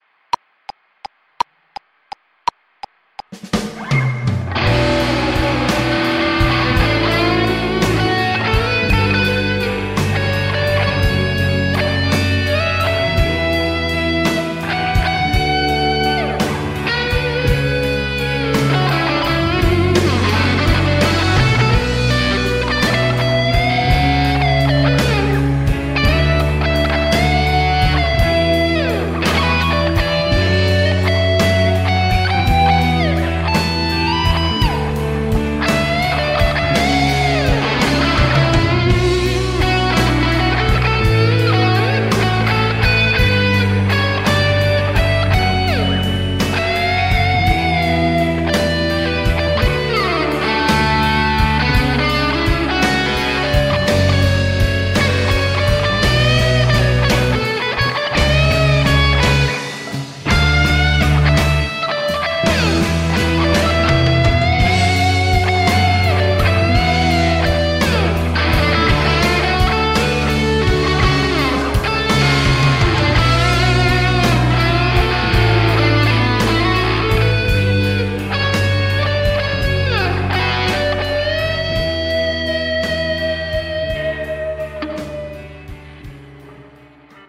- kun osallistut, soita roots-soolo annetun taustan päälle ja pistä linkki tähän threadiin
Väkevä soundi ja soitto ote. Istuu hyvin tähän taustaan.
eeppistä, upea soundi
tunteen paloa, melkein kuin jonkun leffan taustamusiikkia.